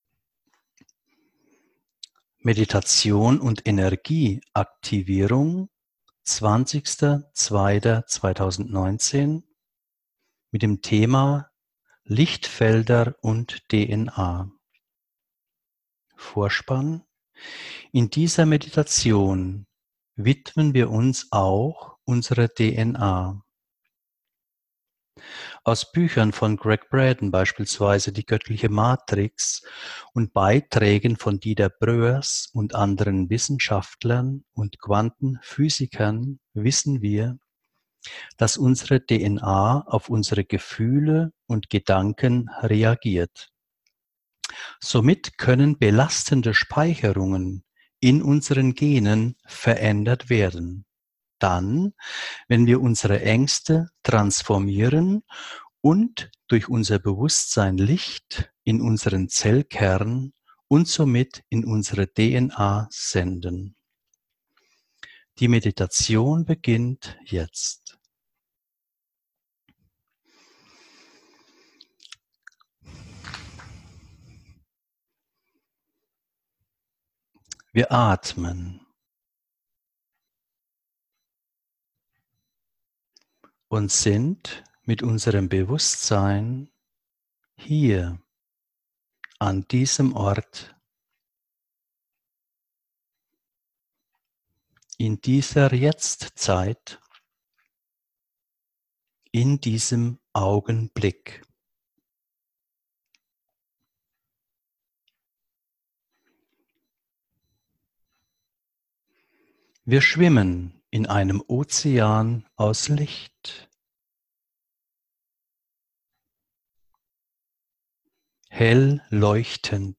Hier eine schöne geführte Meditation zum Thema Lichtfelder und DNA. Einleitung: In dieser Mediation widmen wir uns auch unserer DNA.